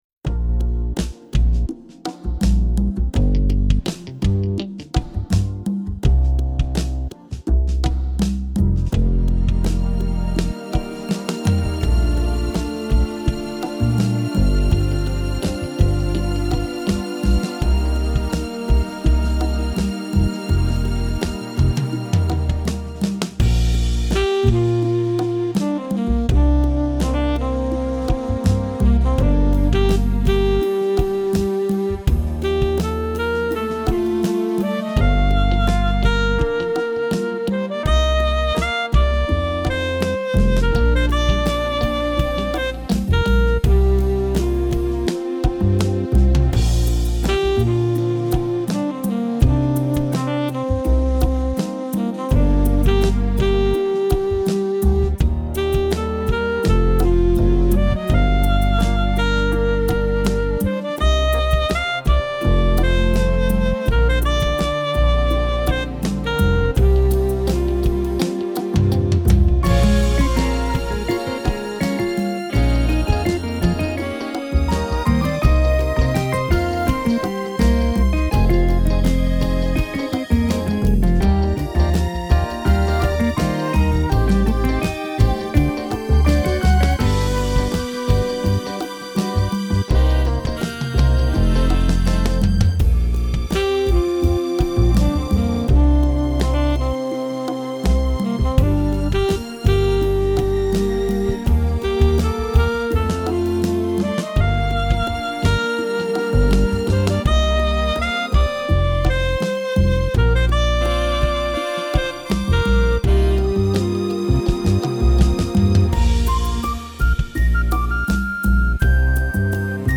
Exploring EZ listening, trying to sooth ravaged nervous system, which seems to be so common, as we age, and still try to take the world seriously. I built a sound palette using 'comfort food' sounds..